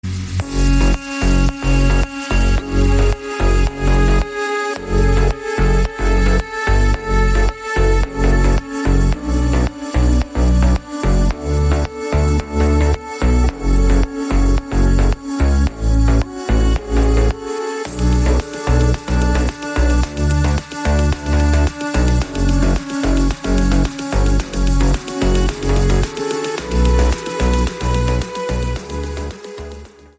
• Качество: 320, Stereo
Стиль: deep house.